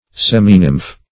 Search Result for " seminymph" : The Collaborative International Dictionary of English v.0.48: Seminymph \Sem"i*nymph`\, n. (Zool.) The pupa of insects which undergo only a slight change in passing to the imago state.